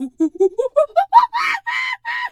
Animal_Impersonations
monkey_2_chatter_scream_09.wav